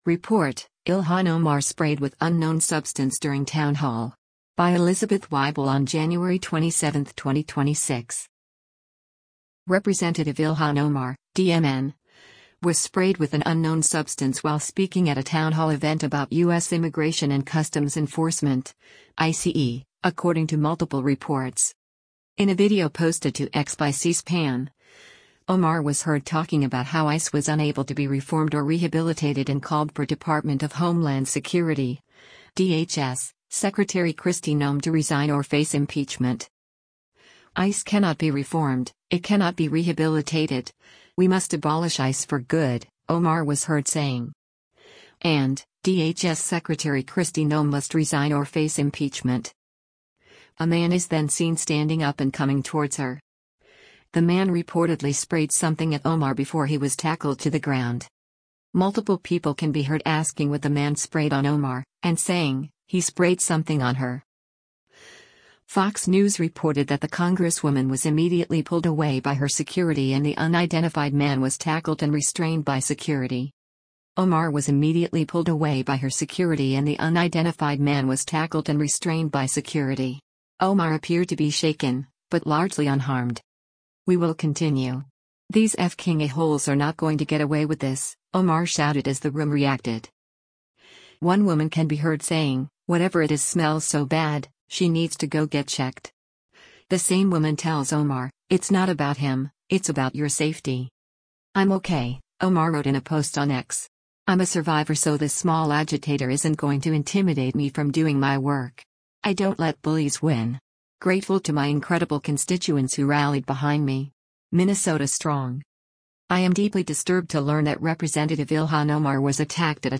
Rep. Ilhan Omar (D-MN) was sprayed with an unknown substance while speaking at a town hall event about U.S. Immigration and Customs Enforcement (ICE), according to multiple reports.
Multiple people can be heard asking what the man sprayed on Omar, and saying, “he sprayed something on her.”
“We will continue. These f–king a–holes are not going to get away with this,” Omar shouted as the room reacted.
One woman can be heard saying, “Whatever it is smells so bad, she needs to go get checked.”